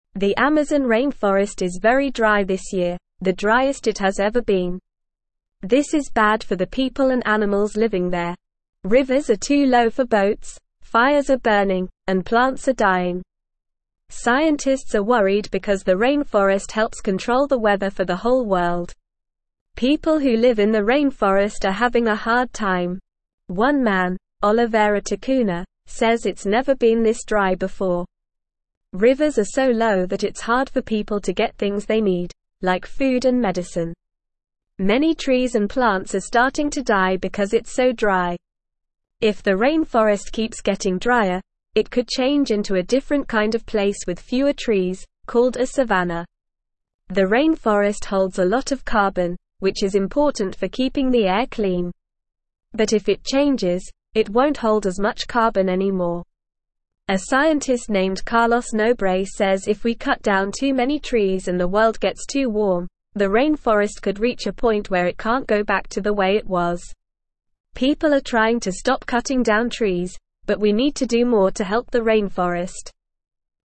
Normal
English-Newsroom-Lower-Intermediate-NORMAL-Reading-Amazon-Forest-in-Trouble-Drought-Fires-and-Concerns.mp3